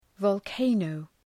Προφορά
{vɒl’keınəʋ}